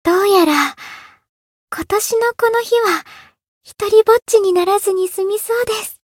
灵魂潮汐-爱莉莎-圣诞节（相伴语音）.ogg